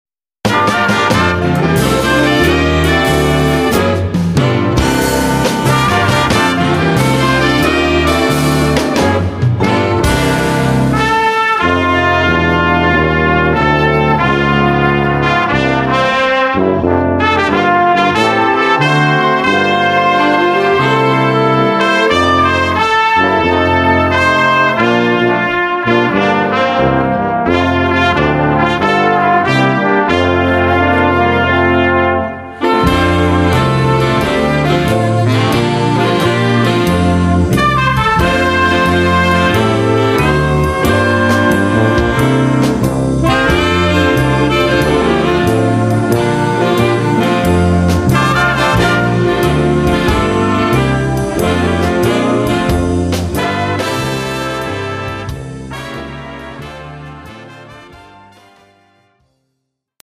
Gattung: Unterhaltungsmusik
Besetzung: Blasorchester